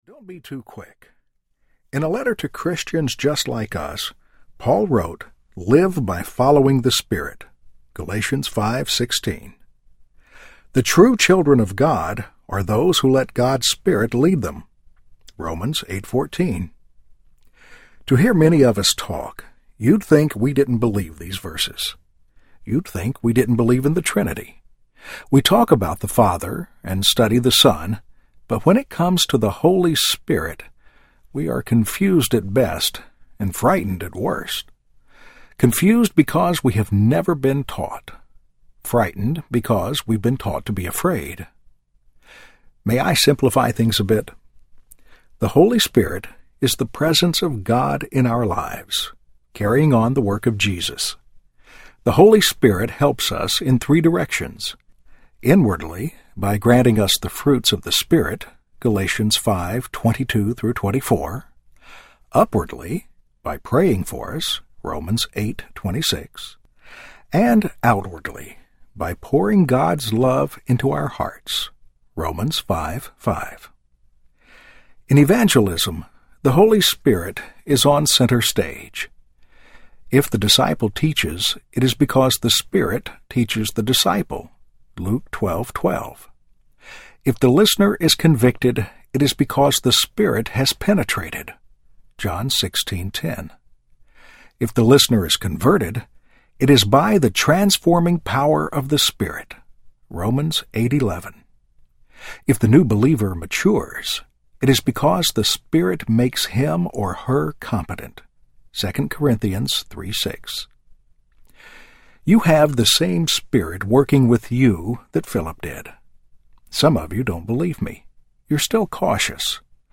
When God Whispers Your Name Audiobook